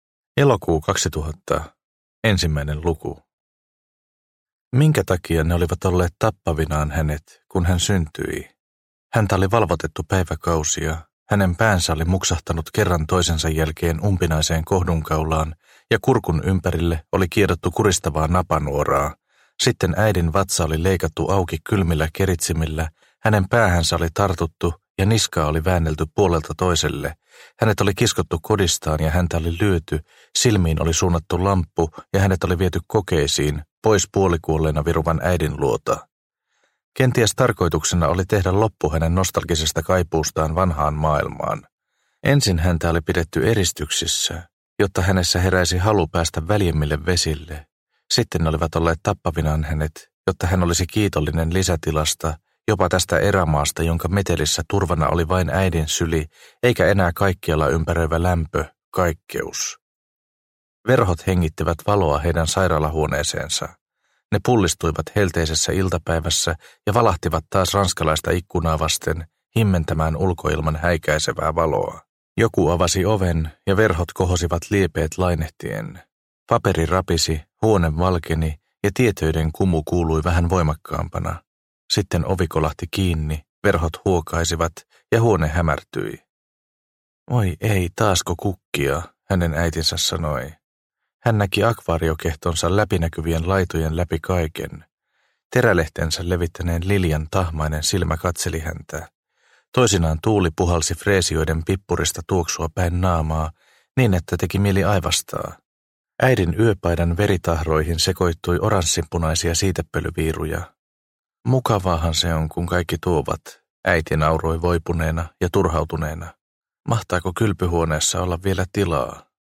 Toivoa sopii – Ljudbok – Laddas ner